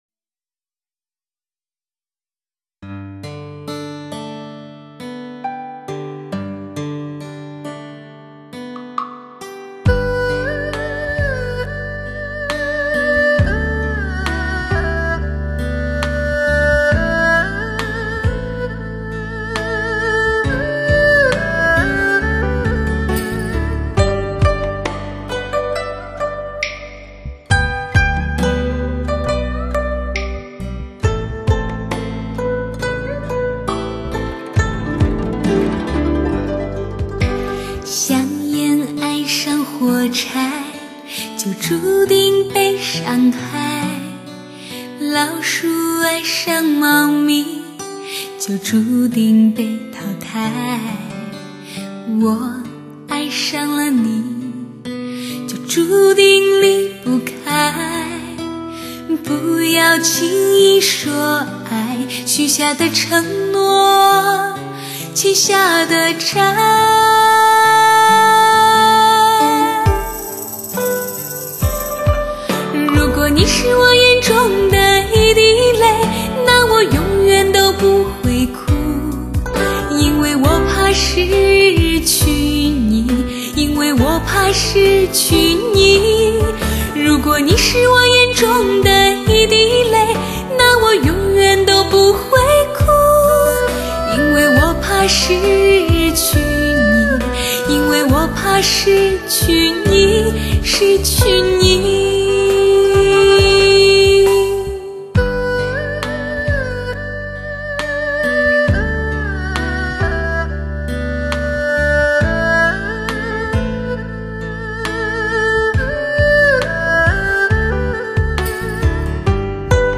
本是快乐的一段情感，却要彼此付出代价，就让我们珍惜生命中每一份情感，用深情的歌声来表达。